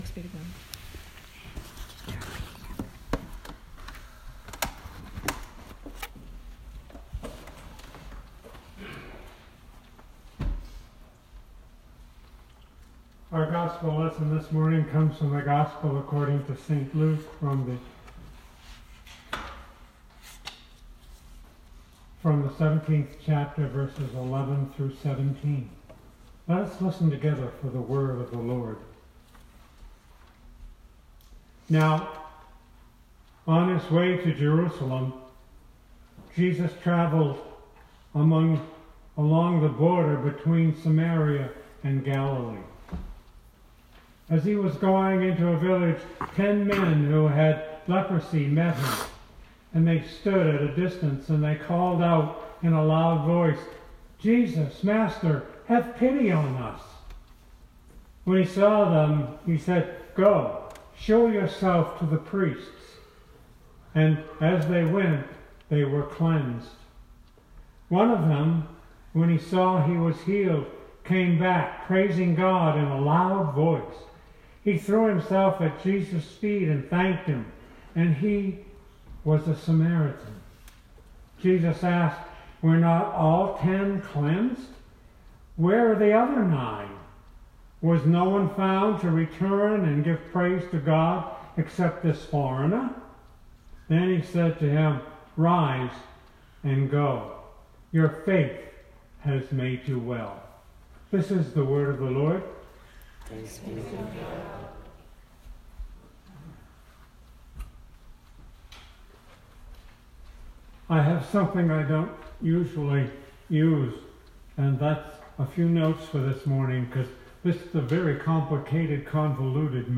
Sermon 2019-10-06